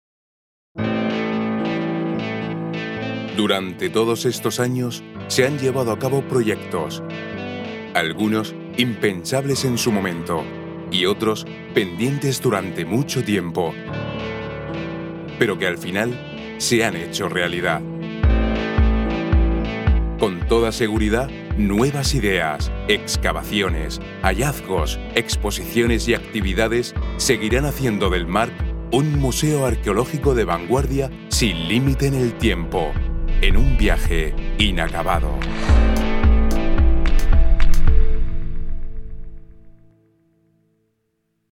Voice actor for television, radio, film commercial recordings. Dramatic interpretation. Company videos, etc
Sprechprobe: Industrie (Muttersprache):
Voice actor who can be kind, energetic, arrogant, corporate, compassionate, rebellious, caring, evil, gentle, persuasive ......